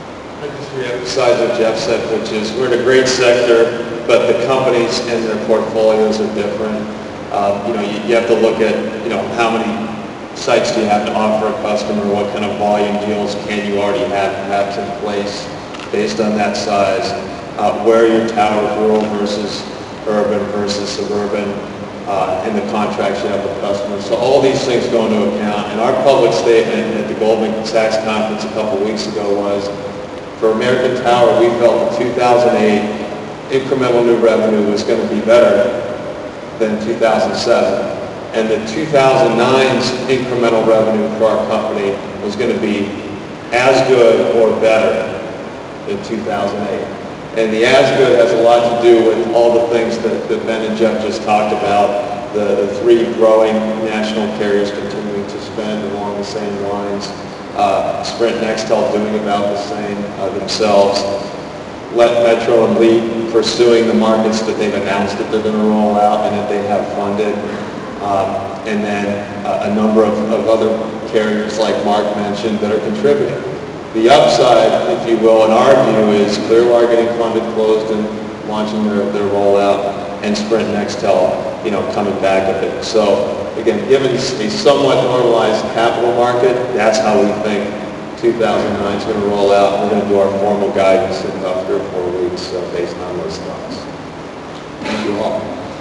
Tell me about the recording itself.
Speaking during the PCIA's final day of The Wireless Infrastructure Show in Hollywood, FL yesterday, the titans of towers were enthusiastic about lease-up opportunities for the coming year.